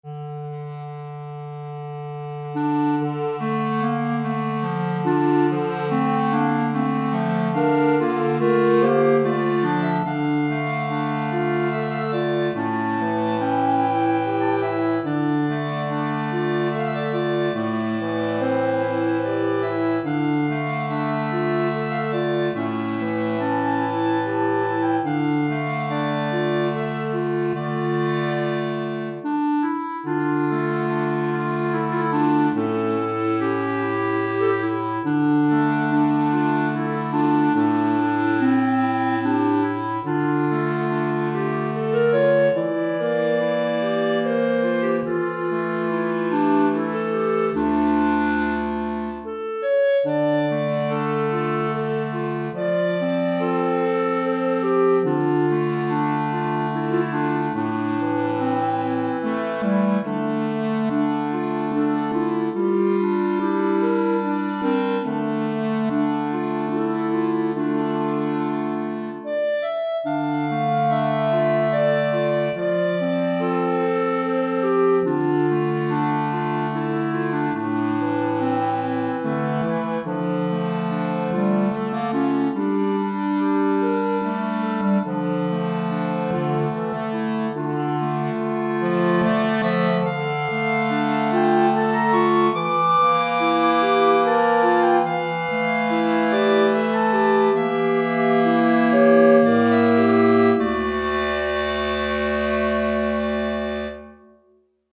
3 Clarinettes en Sib et Clarinette Basse